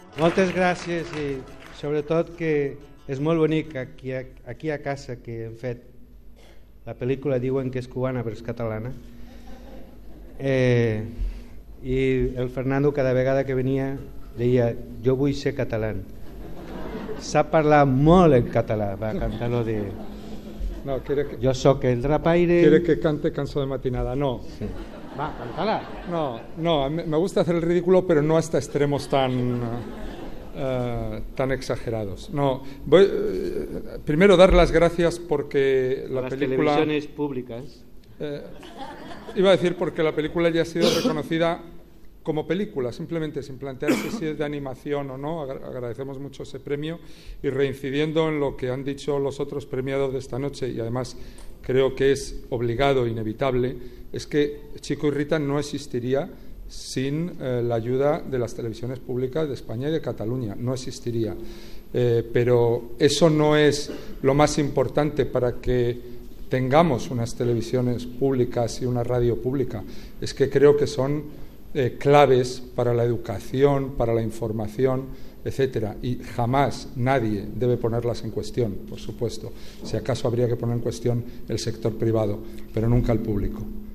Transmissió dels 56 Premis Sant Jordi de Cinematografia de RNE. Parlaments de Xavier Mariscal i Fernando Trueba en recollir el Premi Sant Jordi a la millor pel·lícula de l'any 2011 per "Chico y Rita"